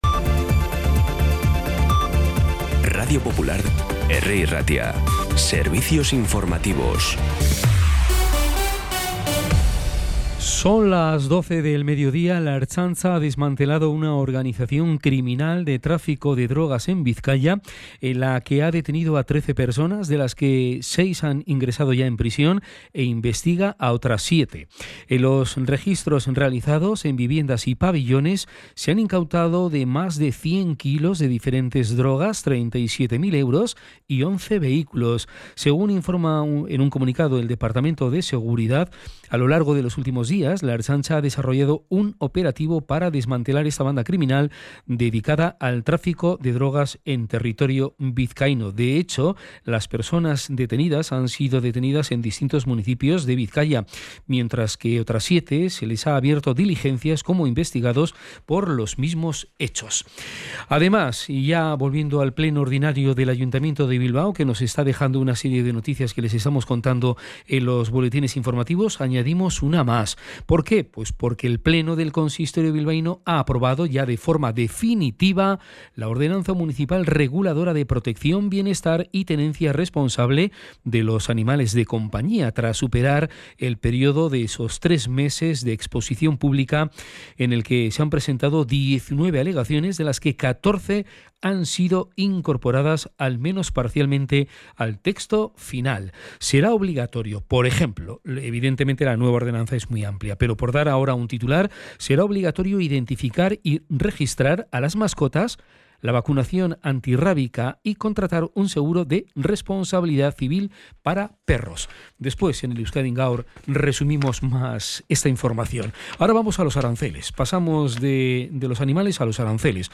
Las noticias de Bilbao y Bizkaia del 27 de marzo a las 12
Podcast Informativos
Los titulares actualizados con las voces del día. Bilbao, Bizkaia, comarcas, política, sociedad, cultura, sucesos, información de servicio público.